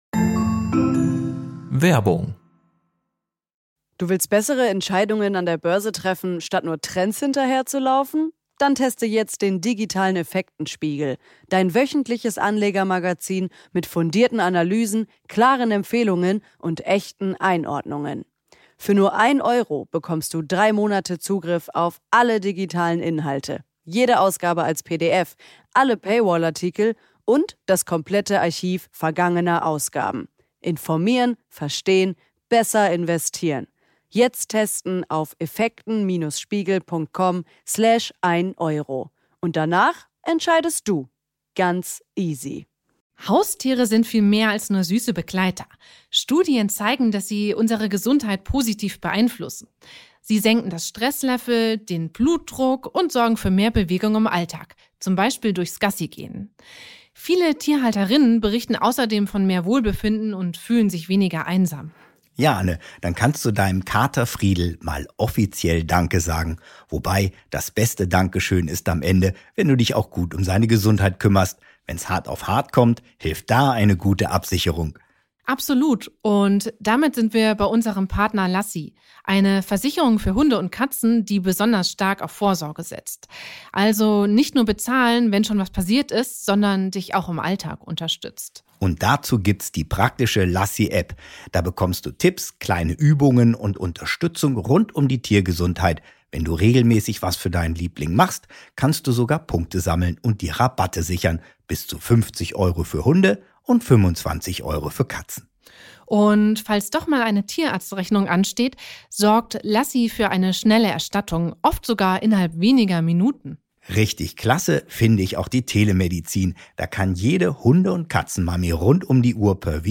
im Gespräch mit Dino-Experte